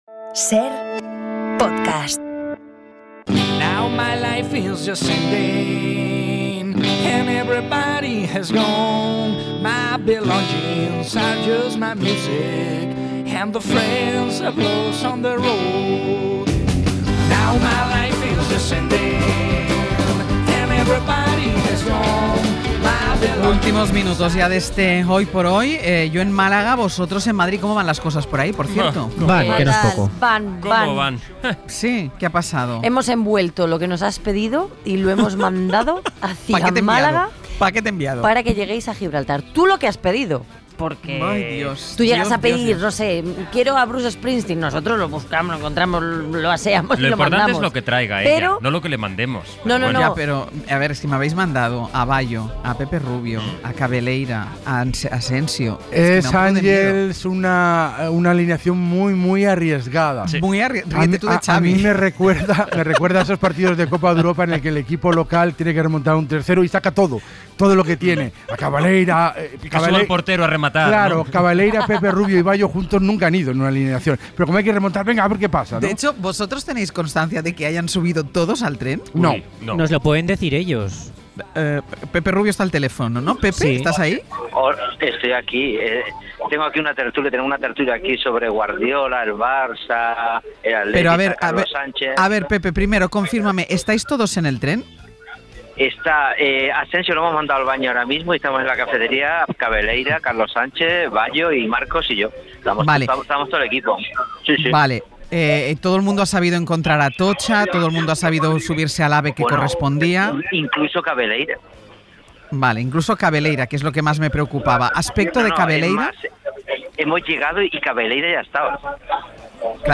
Àngels Barceló y parte de su equipo viajan al sur. Mañana realizan el Hoy por Hoy en Gibraltar. Así suena el programa mientras viaja.